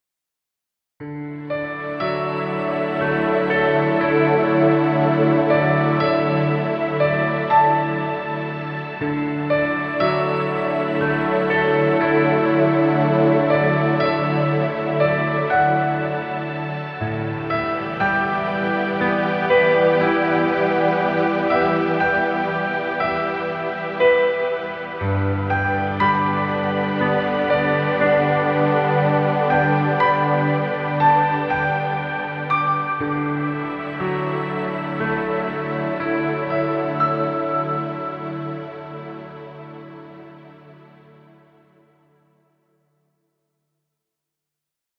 Piano relaxing music. Background music Royalty Free.